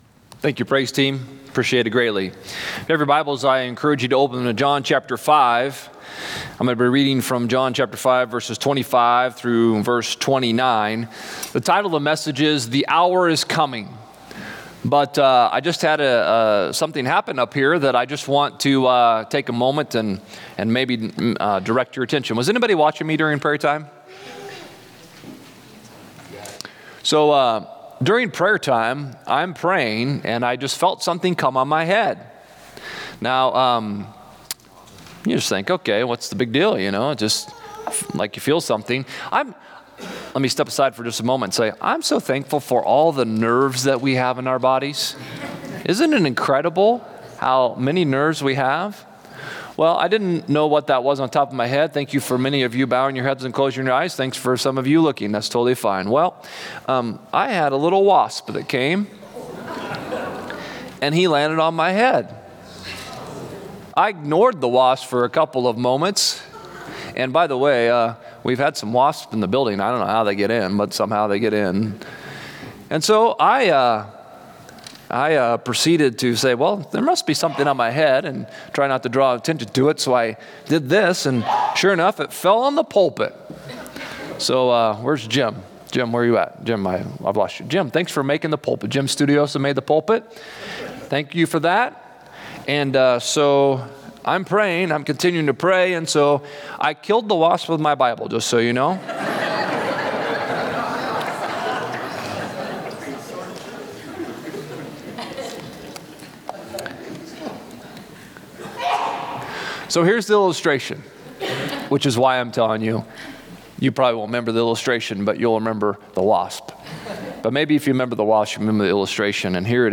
Click Here to Follow Along with the Sermon on the YouVersion Bible App Romans 1:26-27 English Standard Version 26 For this reason God gave them up to dishonorable passions.